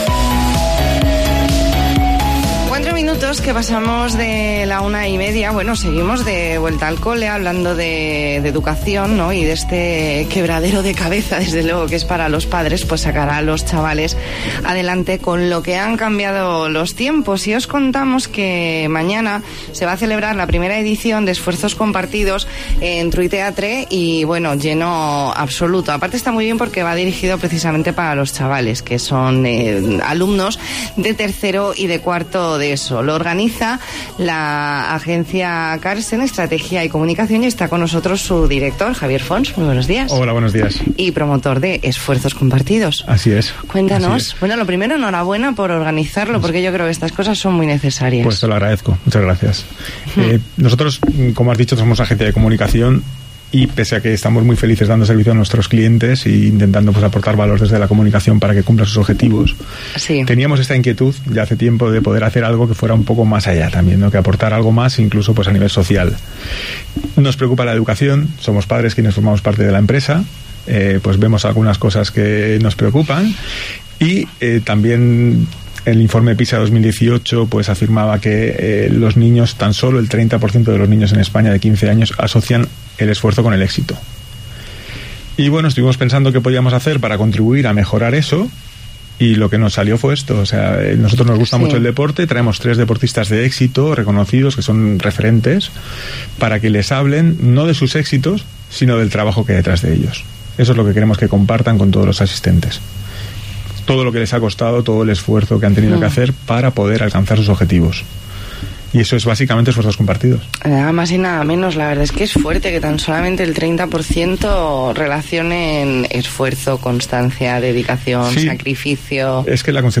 Entrevista en La Mañana en COPE Más Mallorca, lunes 23 de septiembre de 2019.